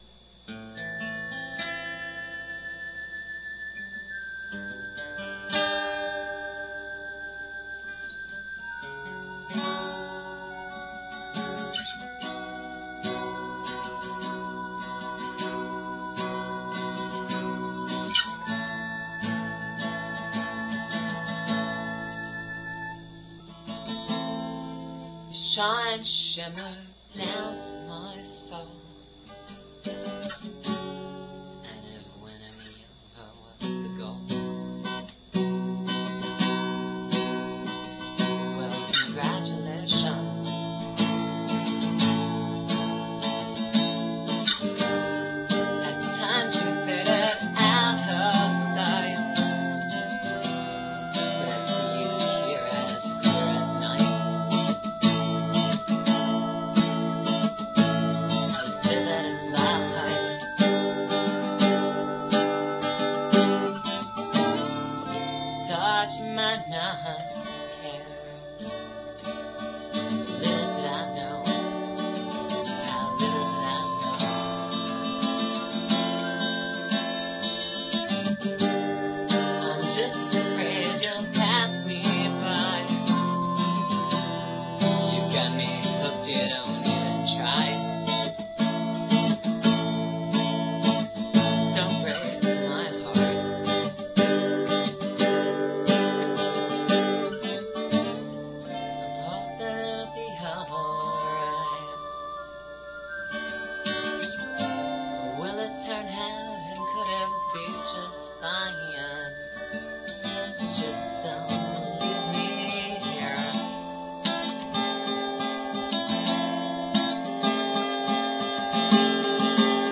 Casio keyboard improvisations
Washburn acoustic guitar(s) and vocals